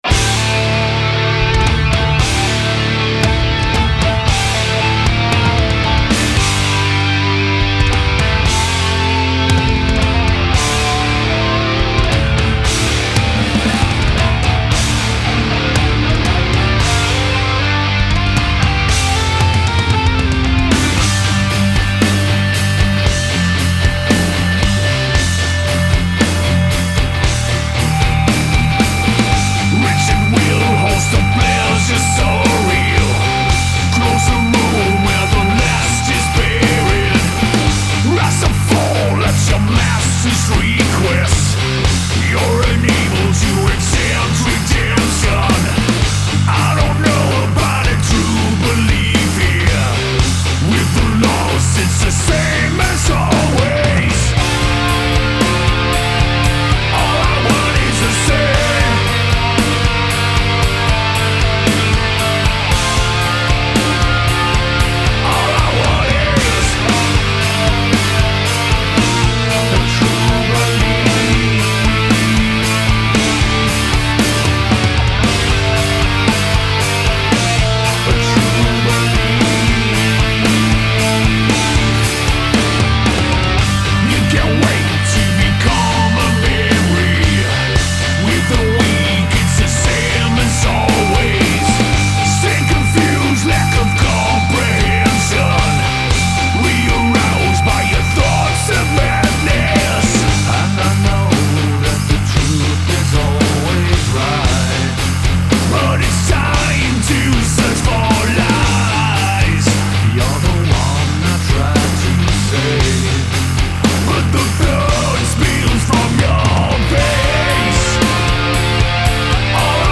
Genre : Metal